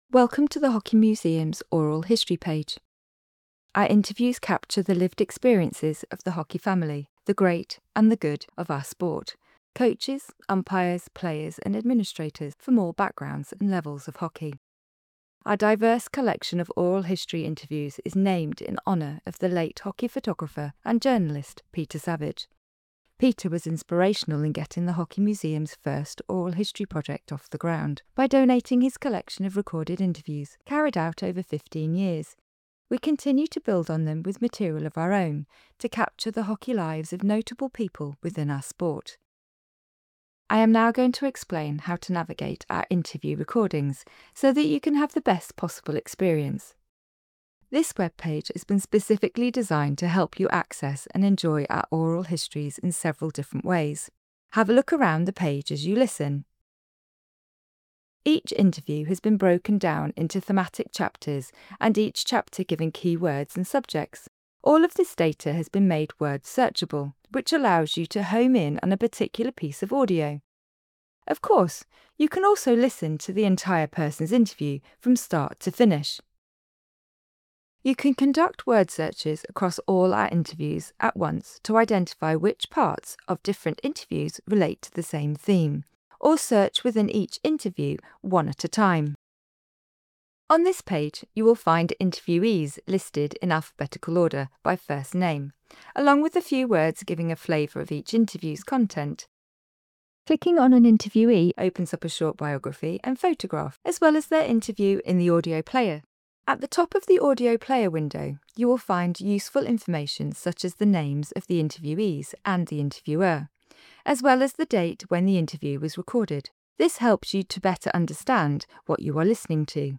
Oral History Interview: INTRODUCTION